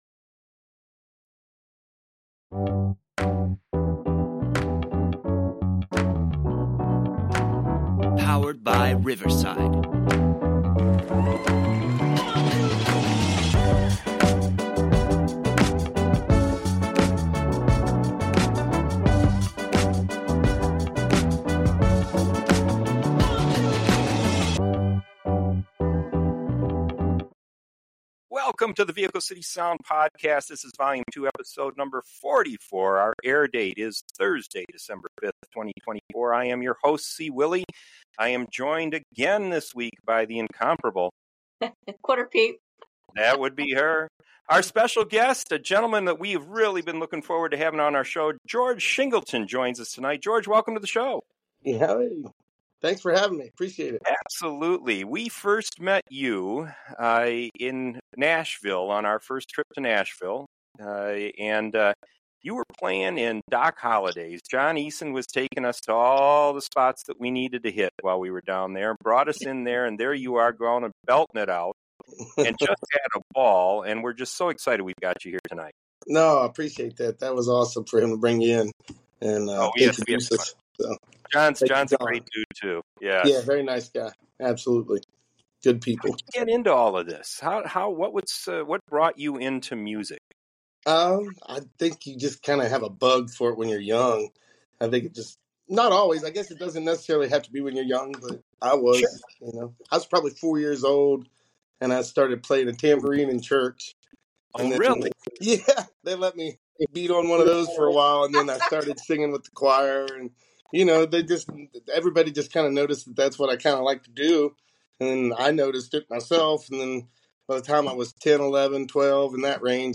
His strong, hard-hitting sound takes you straight to the heart of country music.&nbsp